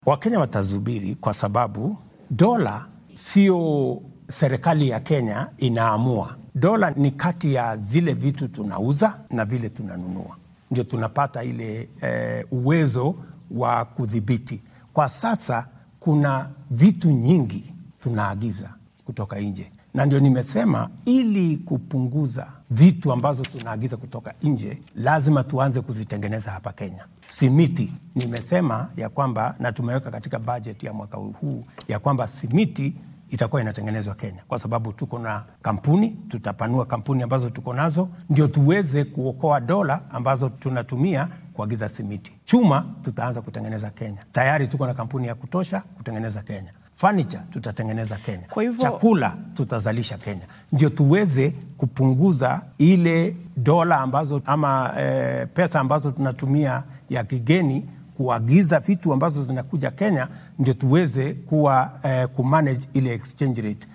Madaxweynaha dalka William Ruto oo wareysi siiyay shirkadaha warbaahineed ee wadanka ayaa ka hadlay arrimo ku saabsan dhaqaalaha , shidaalka, canshuurta iyo sicir bararka nololeed ee jira.